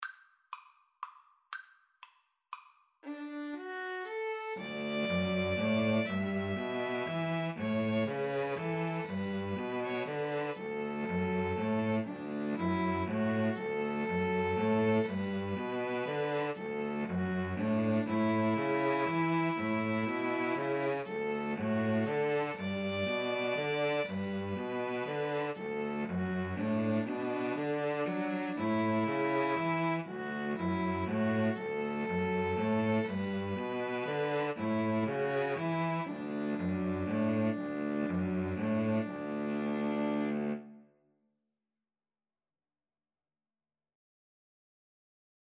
3/4 (View more 3/4 Music)
D major (Sounding Pitch) (View more D major Music for String trio )
= 120 Slow one in a bar
String trio  (View more Easy String trio Music)